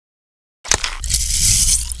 adrenaline_shot_new.wav